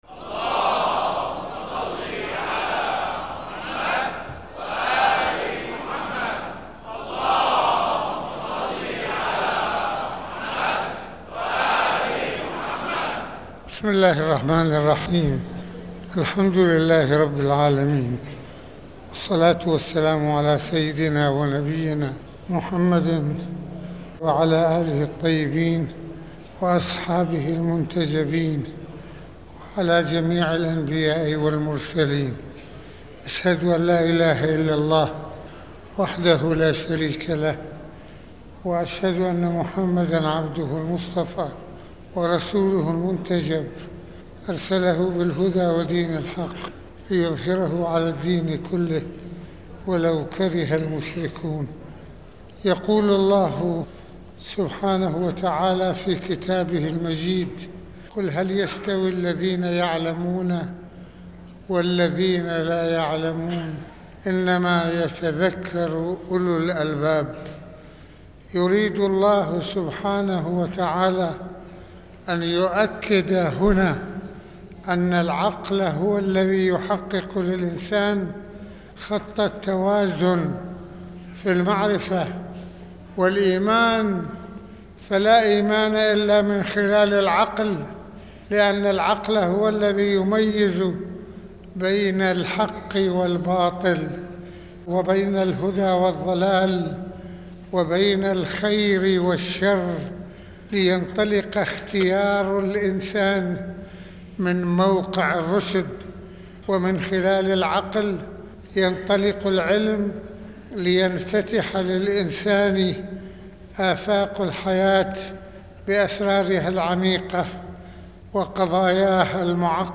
- المناسبة : خطبة الجمعة
المكان : مسجد الإمامين الحسنين (ع)